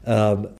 Who is the Mystery Voice?